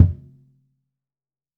Index of /90_sSampleCDs/AKAI S6000 CD-ROM - Volume 5/Brazil/SURDO